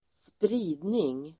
Uttal: [spr'i:dning]